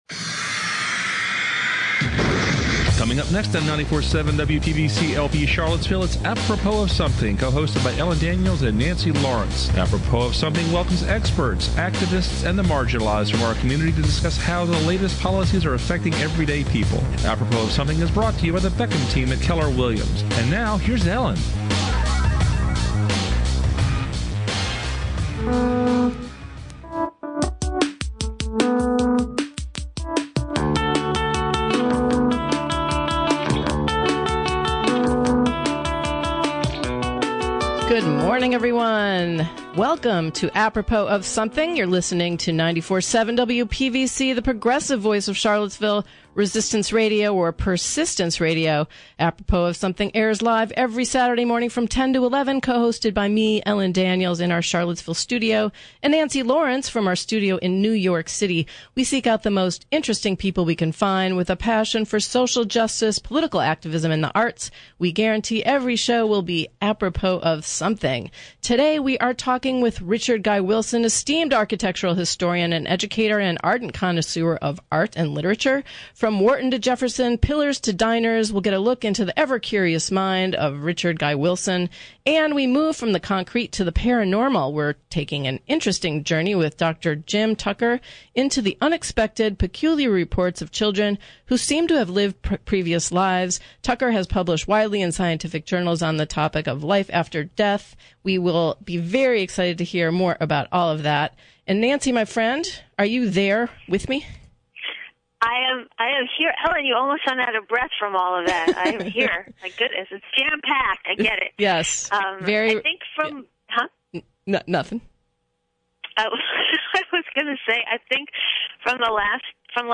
Apropos Of Something seeks out guests who are passionate about the arts, politics and society at-large.